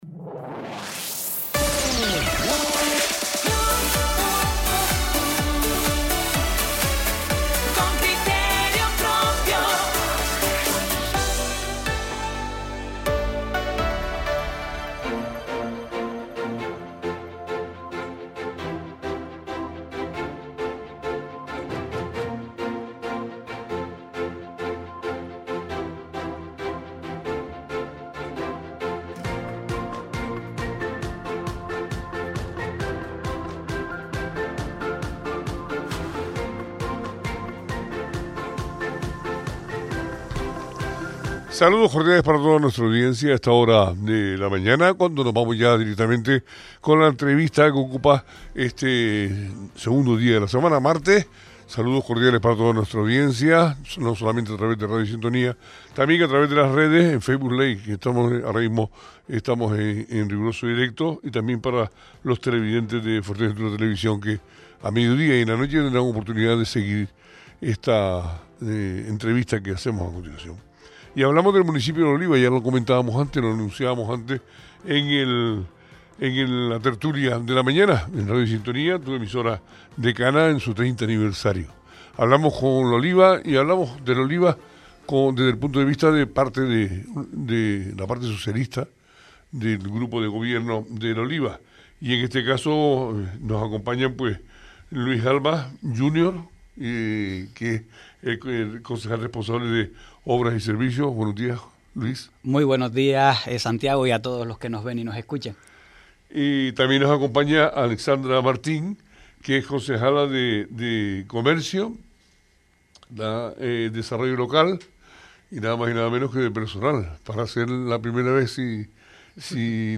Entrevista a Luis Alba concejal de Obras y Servicios y Alexandra Martín concejala de Comercio, Desarrollo Local y Personal del Ayuntamiento de La Oliva. - Radio Sintonía
Entrevistas Entrevista a Luis Alba concejal de Obras y Servicios y Alexandra Martín concejala de Comercio, Desarrollo Local y Personal del Ayuntamiento de La Oliva.